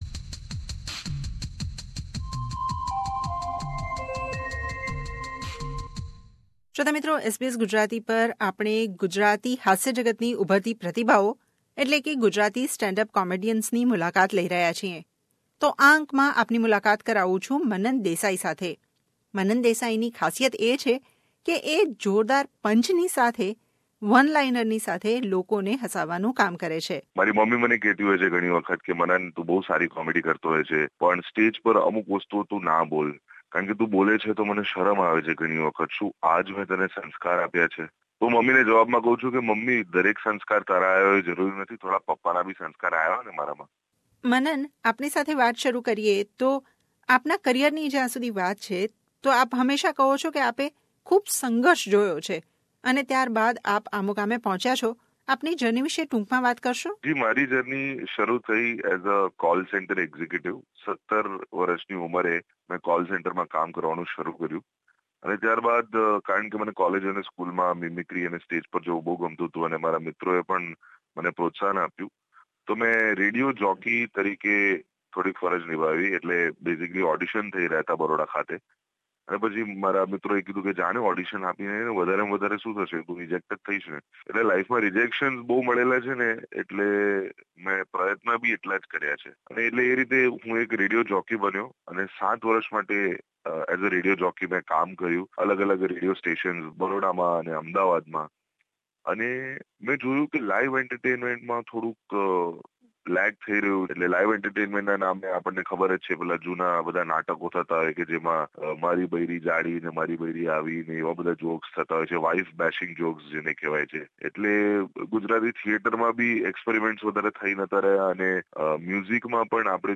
SBS Gujarati સાથે વાત કરતા તેમણે કરેલ સંઘર્ષ વિષે વાત કરી. ગુજરાતી ભાષાના બદલતા સ્વરૂપ અને તેમના ઓસ્ટ્રેલિયા પ્રવાસની તૈયારી અંગે જણાવ્યું હતું.